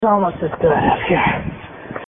In theory, it's the recording of ghost voices.